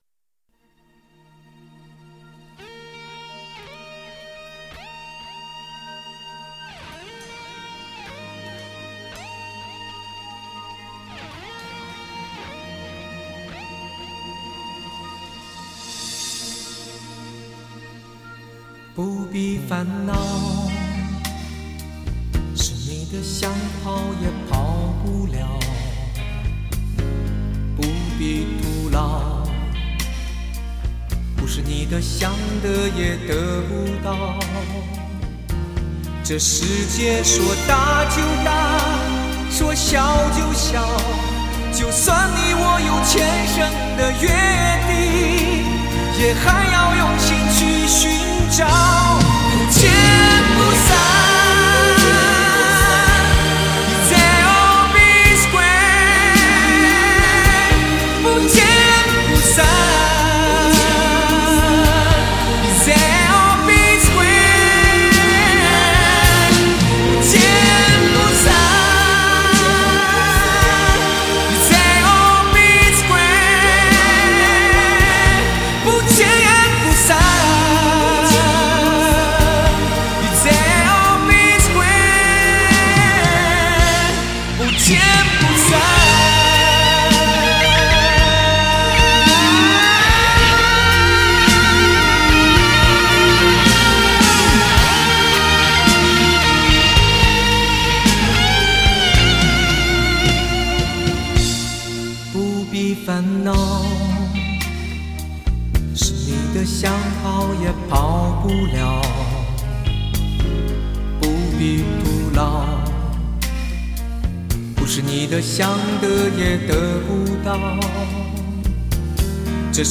给人大气、痛快淋漓的感觉